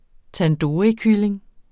Udtale [ tanˈdoːɐ̯i- ]